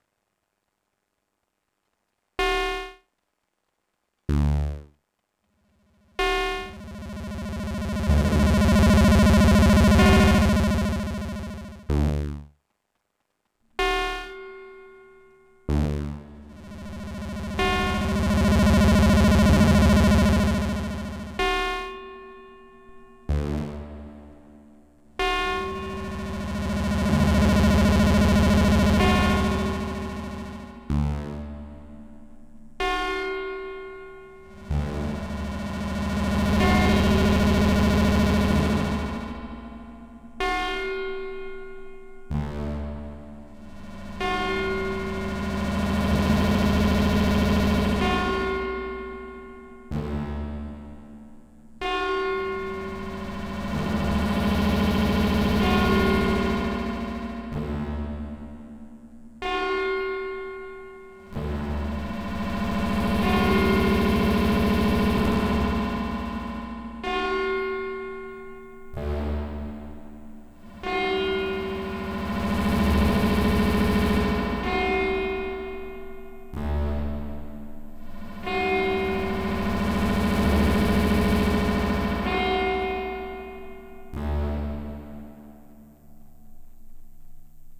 Here is a pair of sound clips comparing the 190 with 2 different sized reverb tanks, using only 100 series modules:
Sound Sample with A4B3C1A "long" tanks.